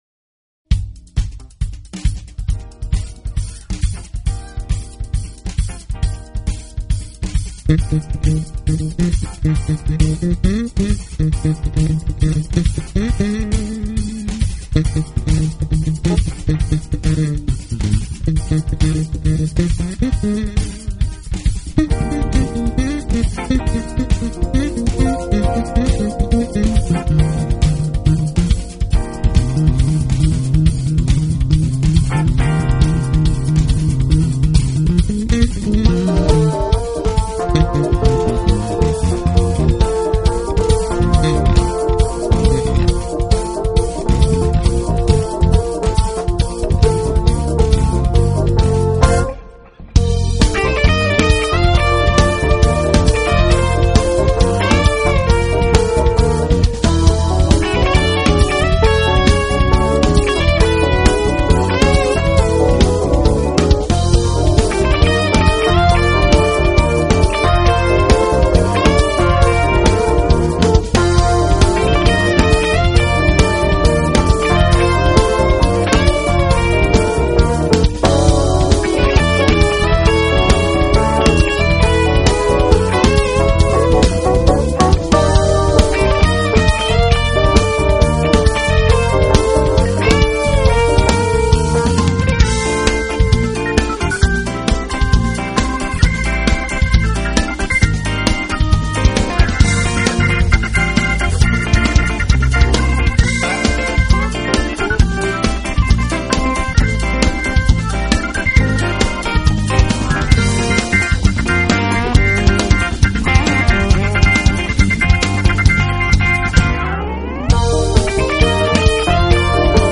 guitars
keyboards
drums
bass
2007年日本东京的现场！